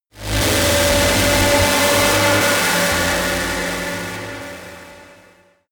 Royalty free sounds: Atmospheres